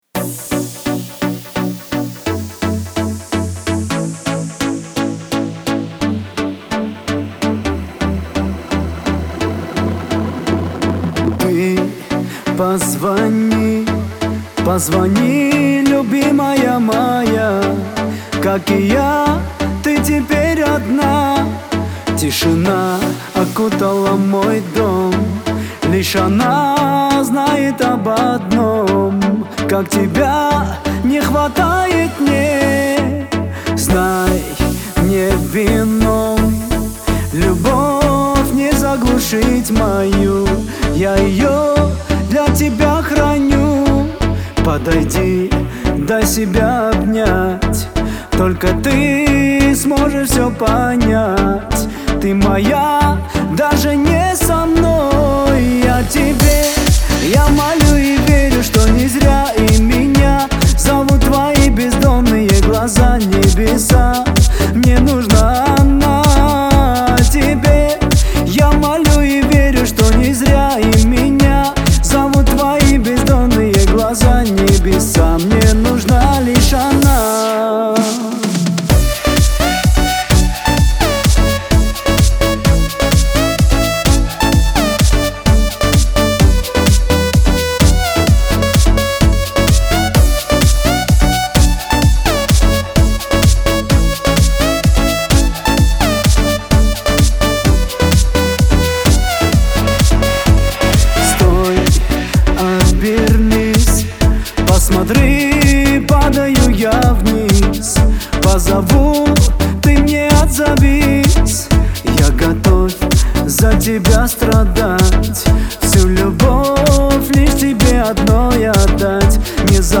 Категория: Популярная музыка